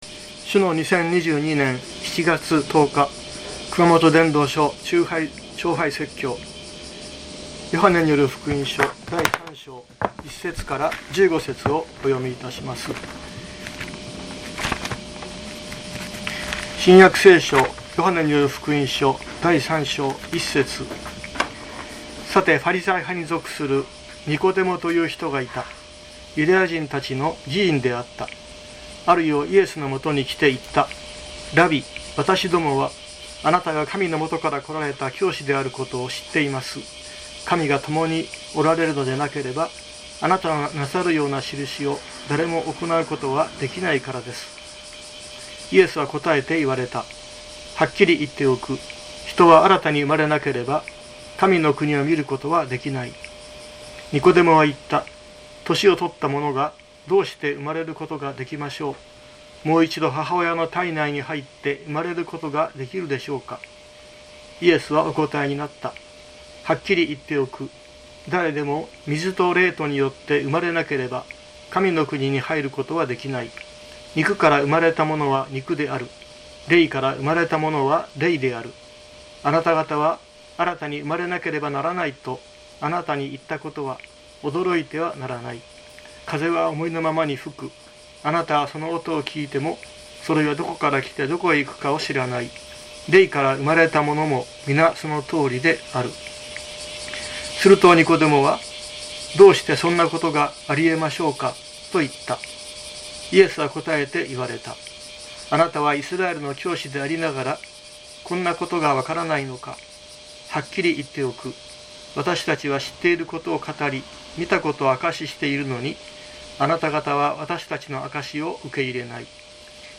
2022年07月10日朝の礼拝「夜の訪問者ニコデモ」熊本教会
説教アーカイブ。